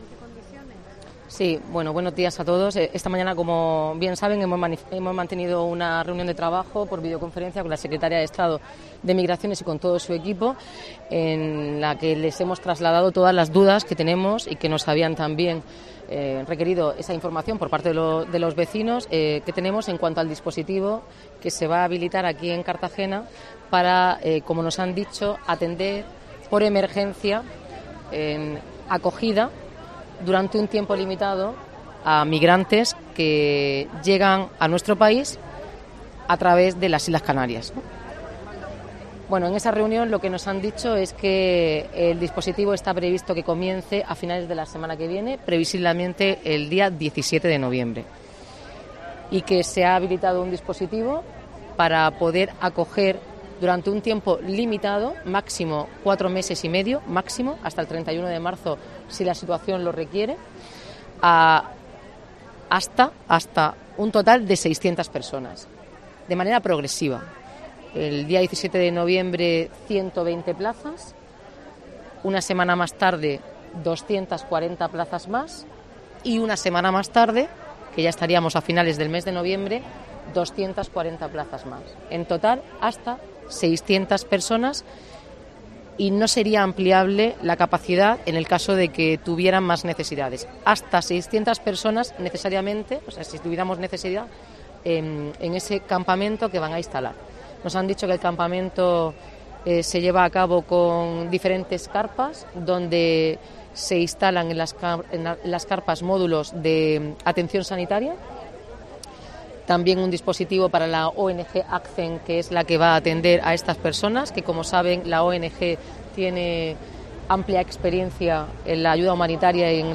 Noelia Arroyo explica la información ofrecida por el Gobierno sobre el campamento de inmigrantes